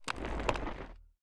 creaking_idle6.ogg